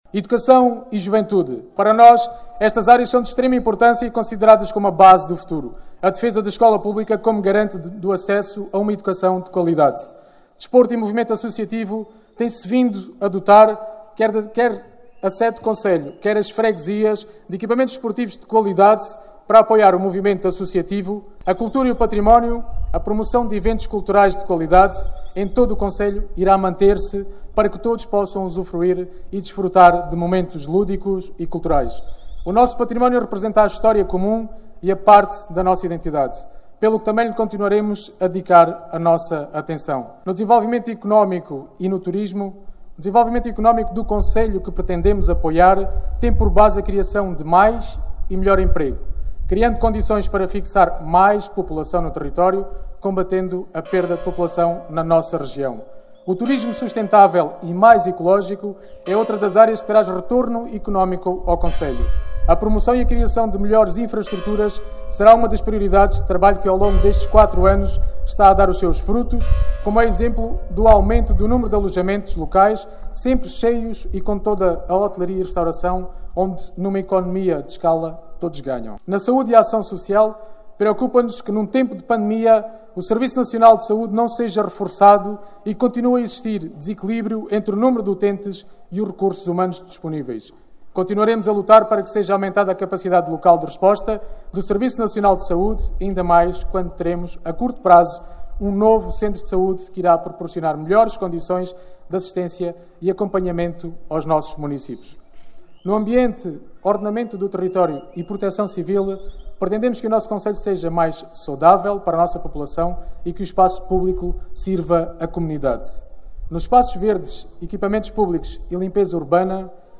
O secretário-geral do PCP Jerónimo de Sousa, esteve no passado sábado, na Vidigueira, numa sessão pública de apoio à recandidatura de Rui Raposo, à Câmara Municipal de Vidigueira.